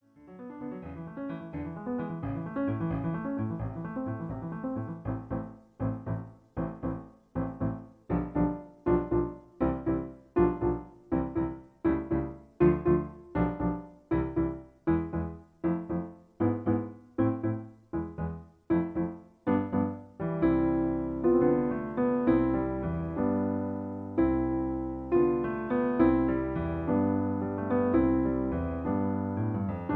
Original Key (A). Piano Accompaniment